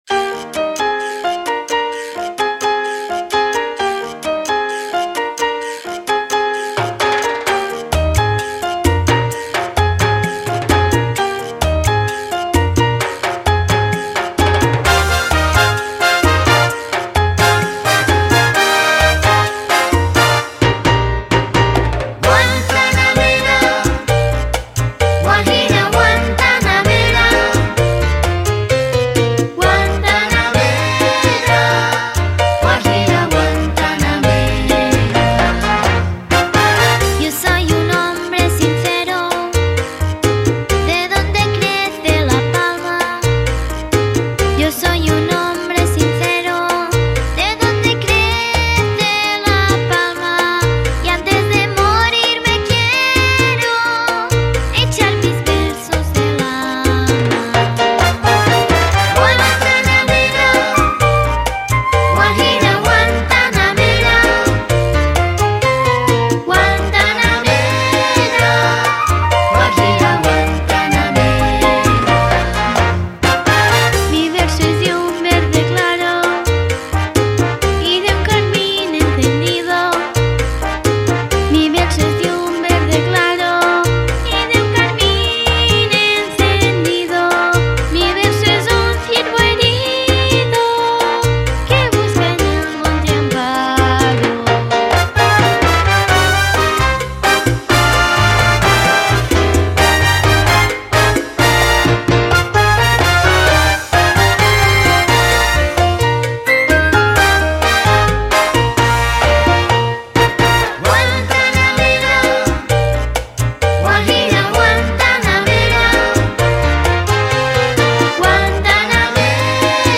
Versió cantada